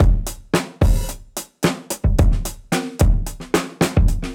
Index of /musicradar/dusty-funk-samples/Beats/110bpm
DF_BeatB_110-03.wav